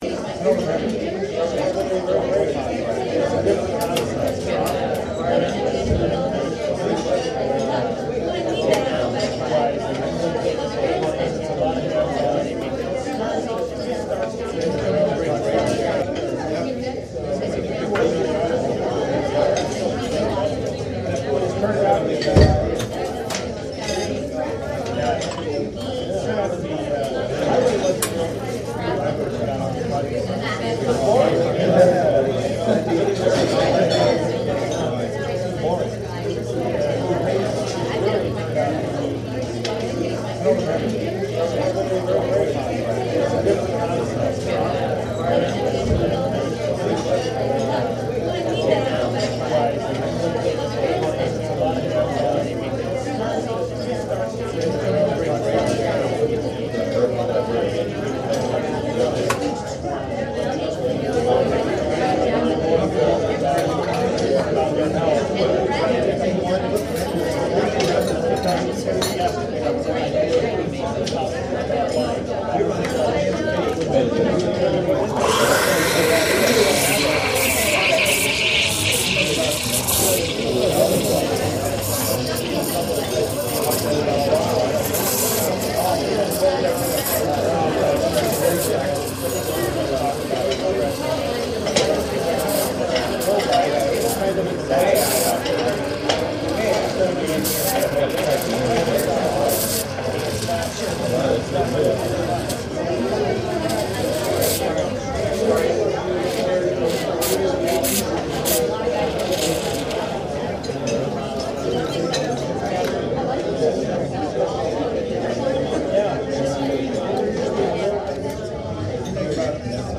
CoffeeShop.mp3